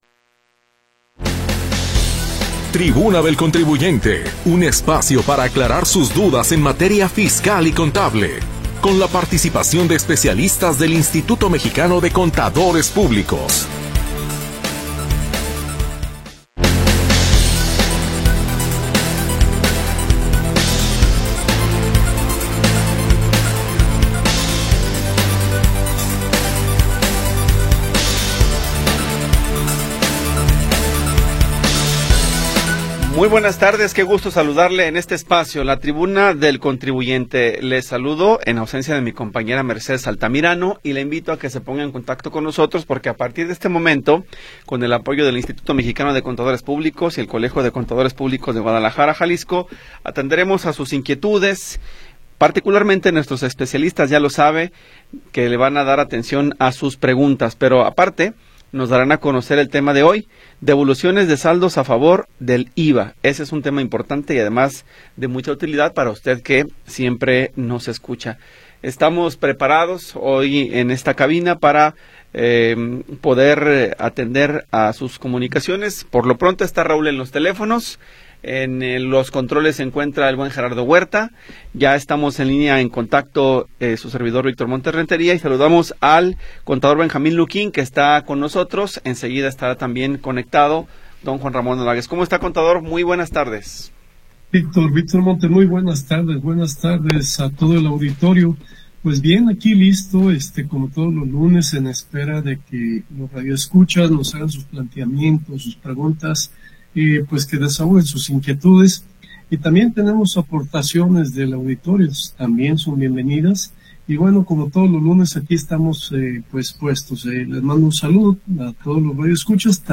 Orientación legal y contable con la participación de especialistas del Instituto Mexicano de Contadores.
Programa transmitido el 18 de Agosto de 2025.